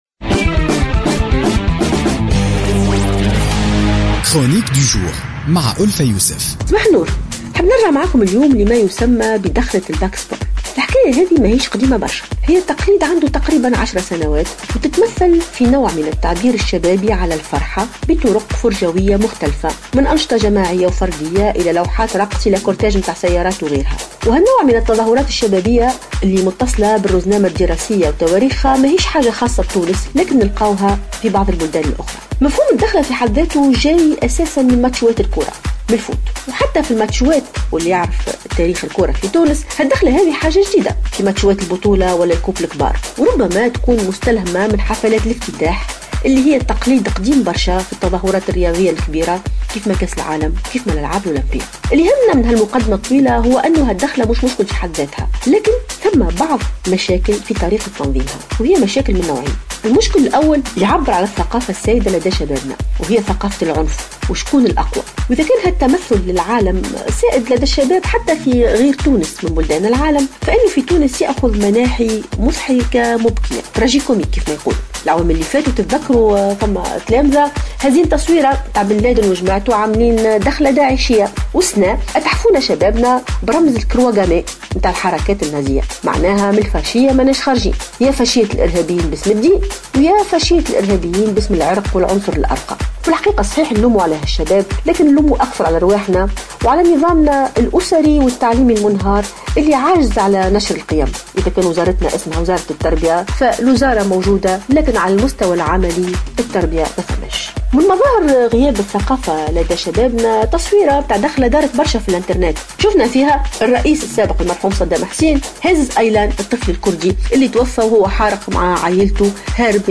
تطرقت الأستاذة ألفة يوسف في افتتاحية اليوم الخميس 21 أفريل 2016 إلى الإنزلاقات التي شابت دخلات "الباك سبور" هذا العام مؤكدة أنها تنم عن جهل ولاوعي لدى شبابنا ونقص في الزاد المعرفي والثقافي لهؤلاء التلاميذ .